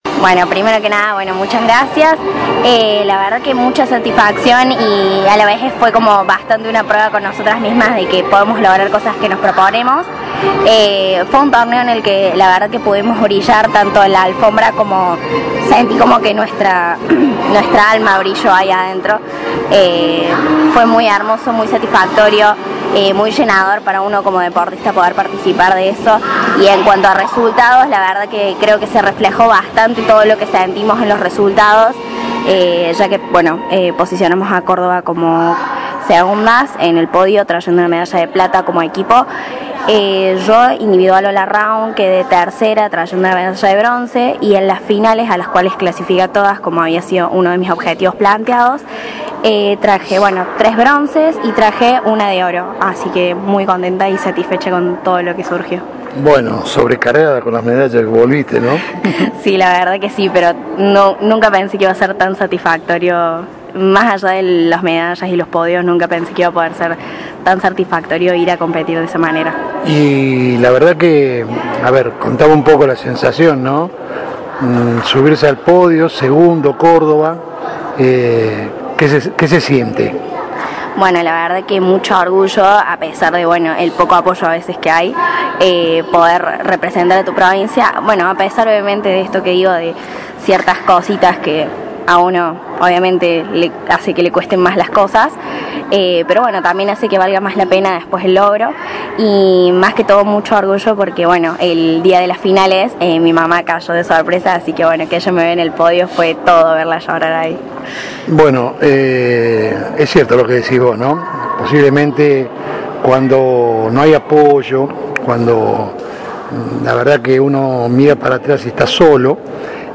-Audio de la nota con la gimnasta